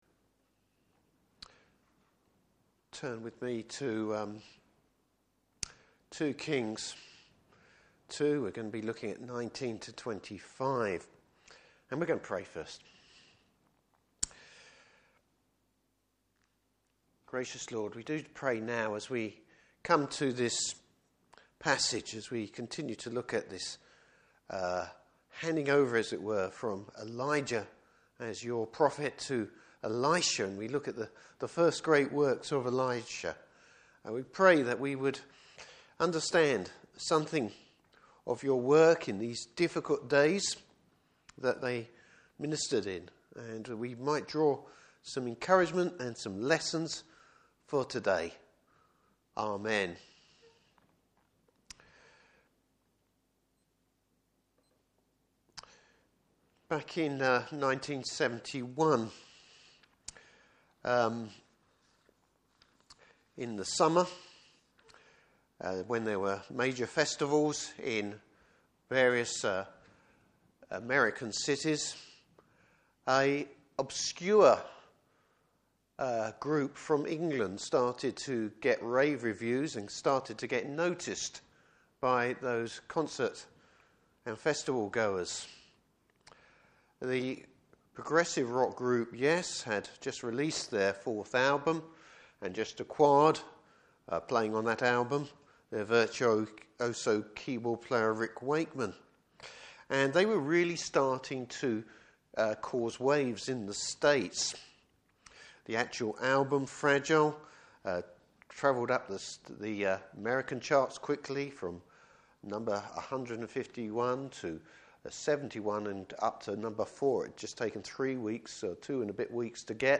Service Type: Evening Service Bible Text: 2 Kings 2:19-25.